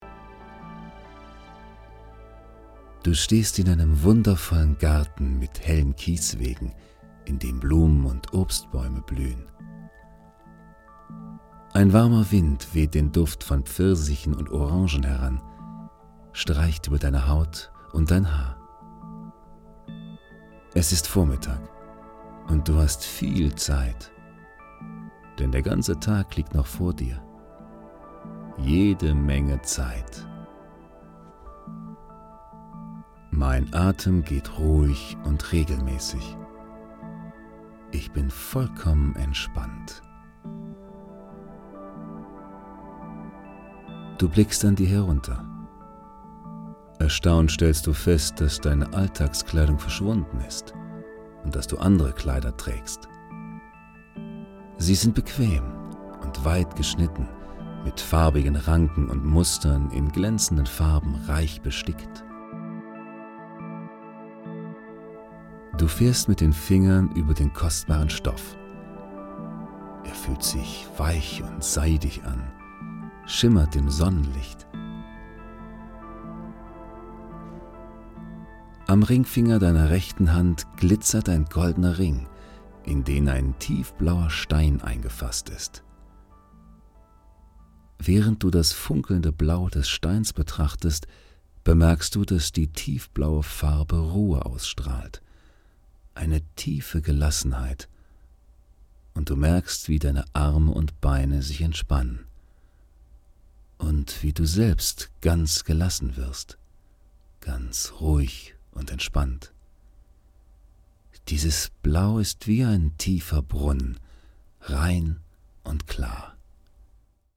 Phantasiereise: Palast des Reichtums Geführte Meditation und Entspannungstechnik mit Hypnose und Suggestionen